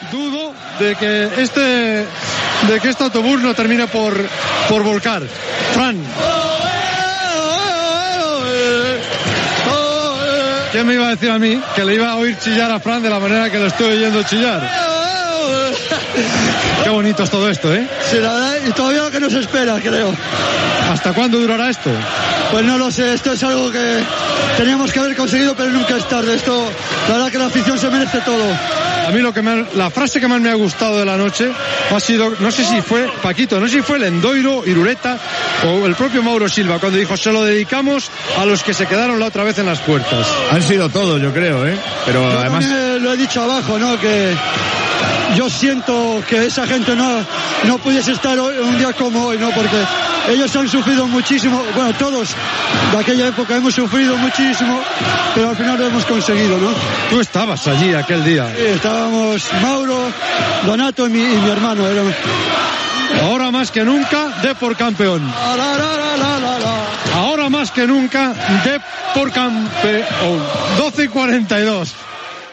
Transmissió de la celebració del títol de lliga de primera divsió de futbol masculí per part del Deportivo de La Coruña, a la temporada 1999-2000.
Esportiu